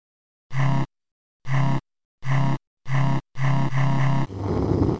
fuseend.mp3